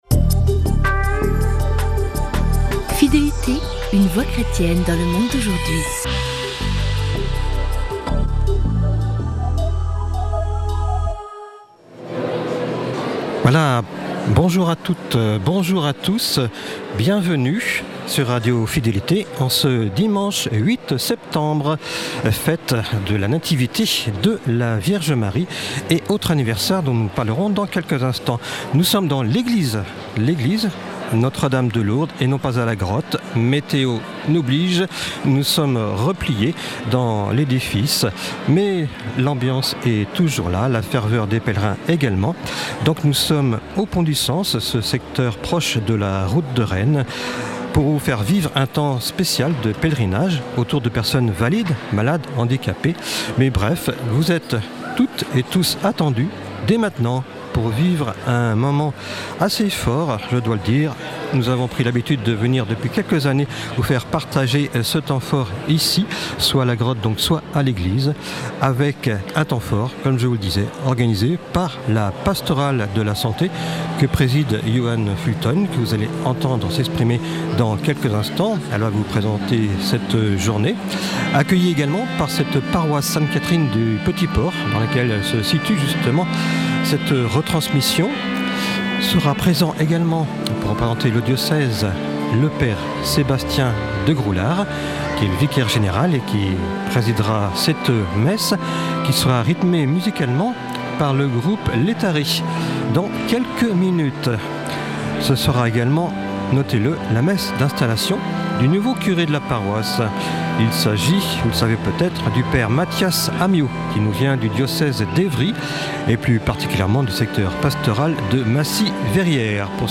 Malgré le météo défavorable, nous avons pu s’adapter et la messe a eu lieu à l’église Notre-Dame de Lourdes suivie d’un bon pique-nique partagé en toute simplicité, fraternité et convivialité dans les salles.
ND-de-Lourdes-Messe-du-8-septembre-2024.mp3